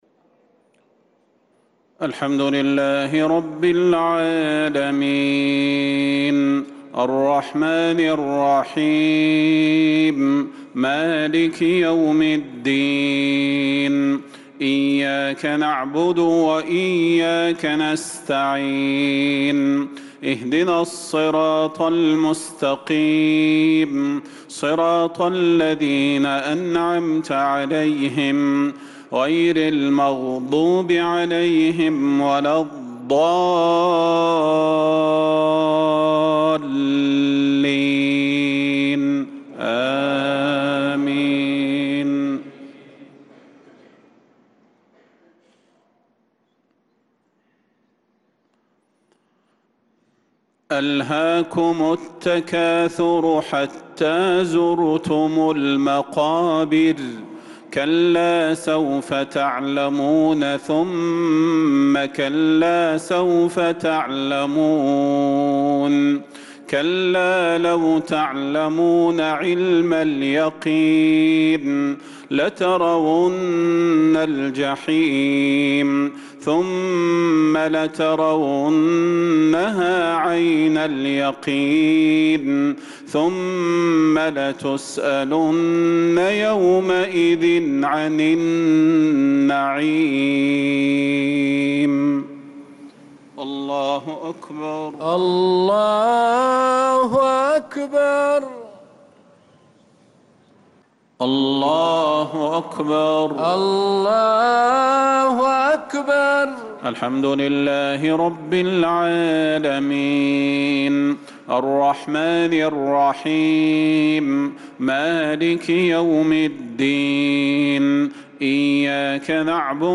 صلاة المغرب للقارئ صلاح البدير 23 ذو القعدة 1445 هـ
تِلَاوَات الْحَرَمَيْن .